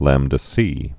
(lămdə-sē)